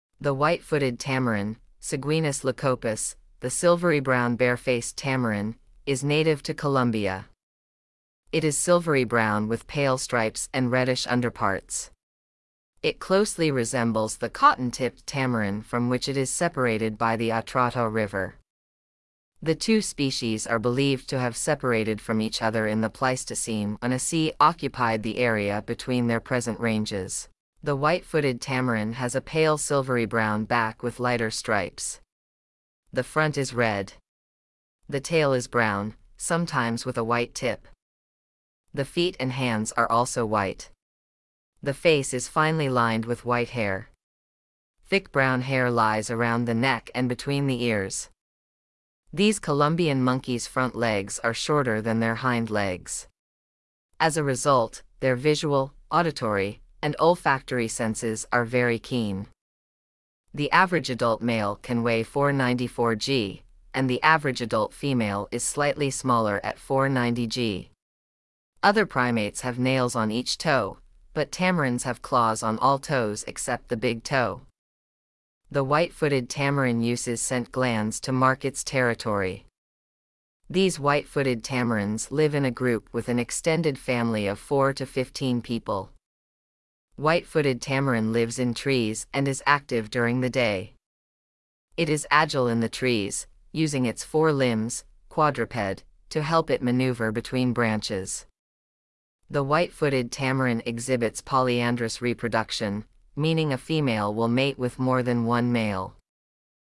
Silvery Brown Bare-face Tamarin
Silvery-Brown-Bare-face-Tamarin.mp3